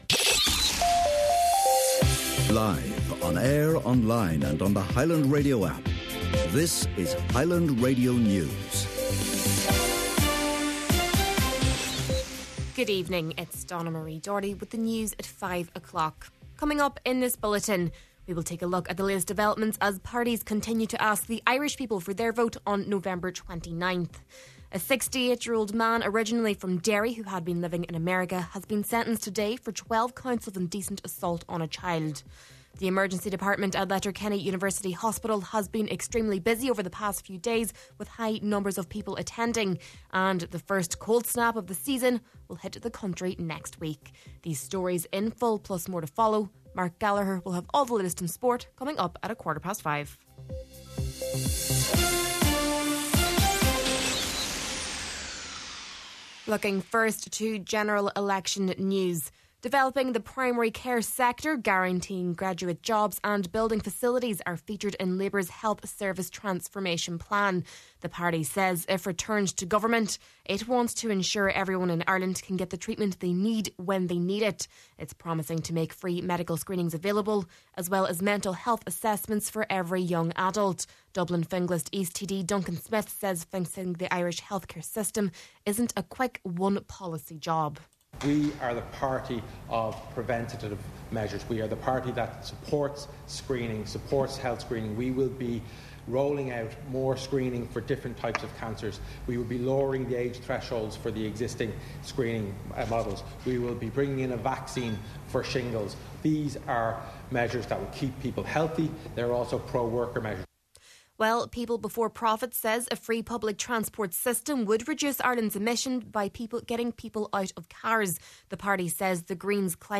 Main Evening News, Sport, and Obituary Notices – Friday, November 15th